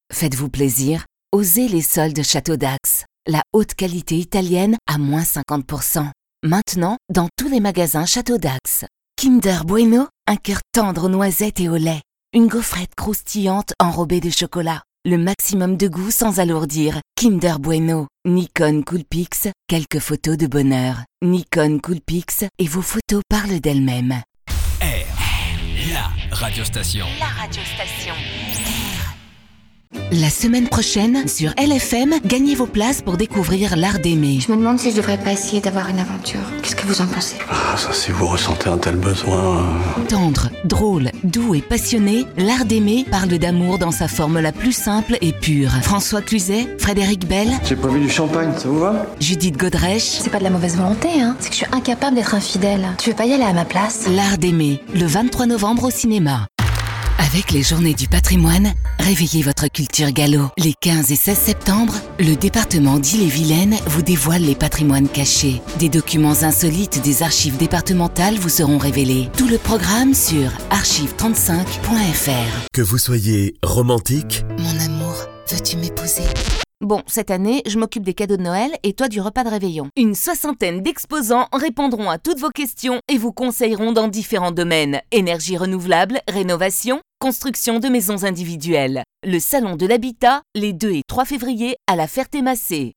Voice over depuis 1988, je peux aussi bien enregistrer des messages sur un ton jeune et dynamique que grave et posé.
Kein Dialekt
Sprechprobe: Werbung (Muttersprache):